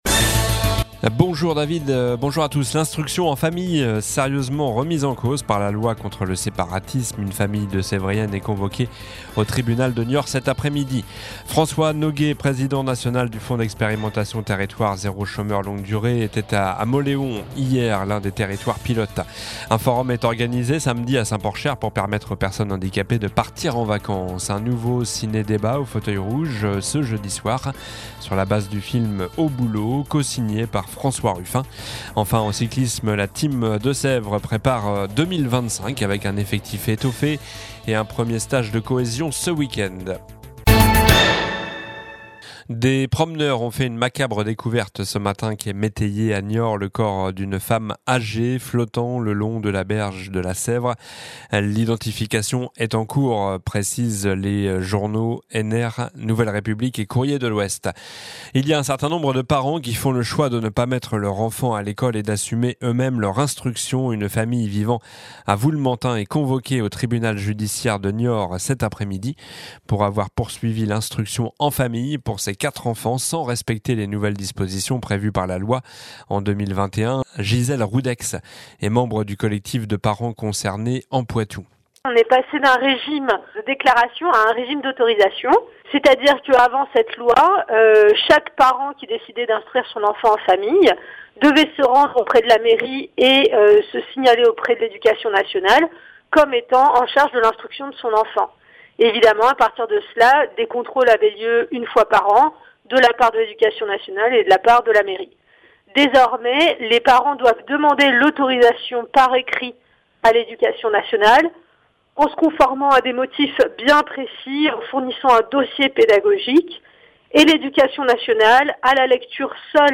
Journal du jeudi 28 novembre (midi)